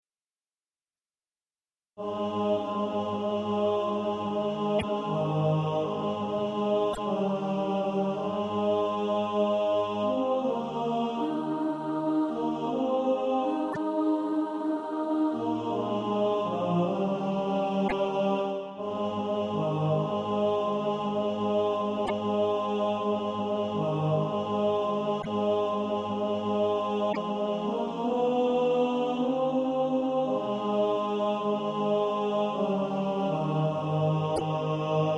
(SATB) Author
Tenor Track.